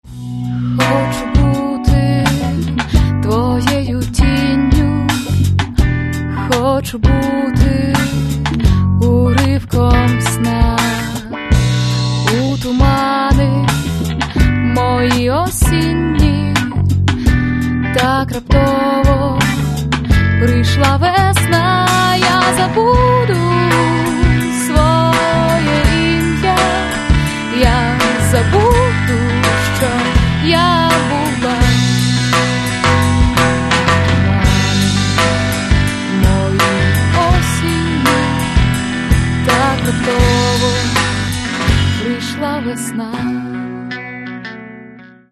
Українська Сучана Лірична Пісня.